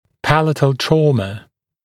[‘pælətl ‘trɔːmə][‘пэлэтл ‘тро:мэ]травма нёба, травмирование нёба